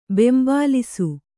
♪ bembālisu